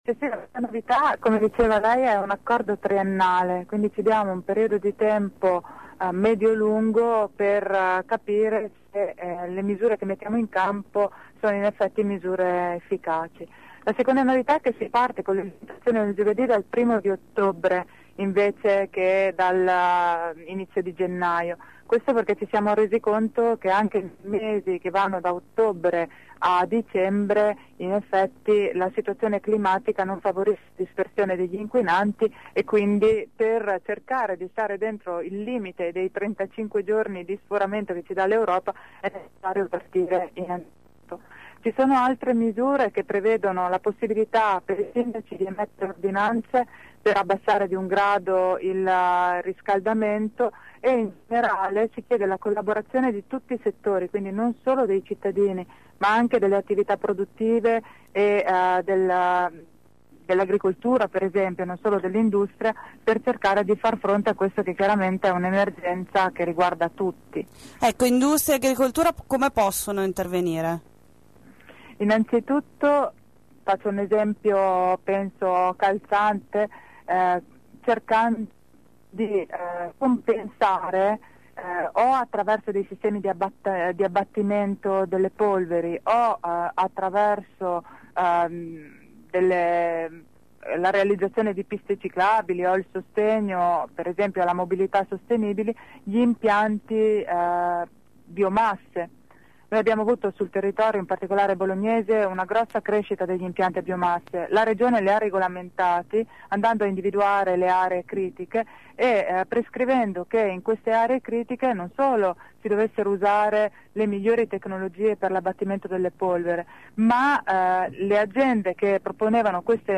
Per presentare il provvedimento questa mattina è stata nostra ospite durante AngoloB l’assessore regionale all’ambiente, Sabrina Freda.